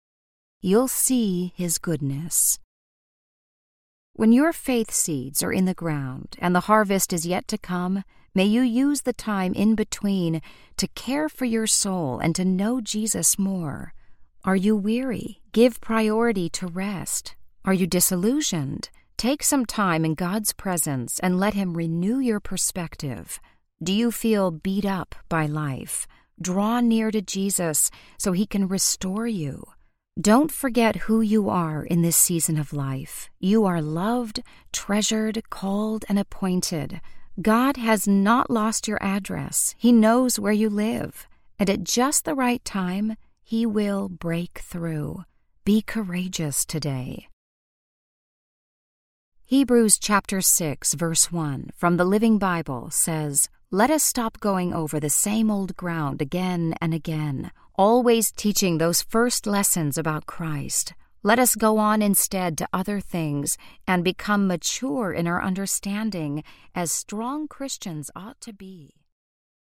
Blessings for the Soul Audiobook
Narrator
1.5 Hrs. – Unabridged